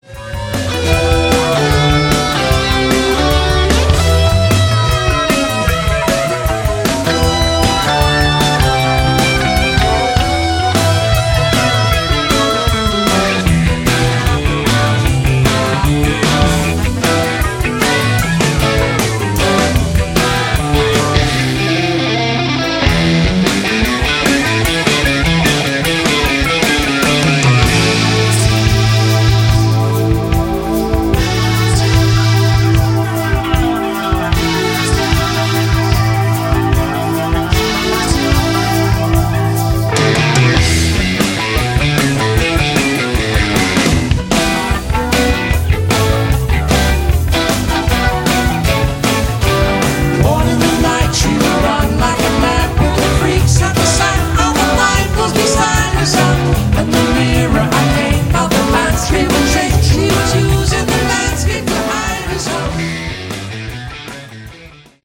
Category: Prog Rock